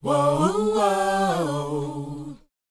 WHOA D A.wav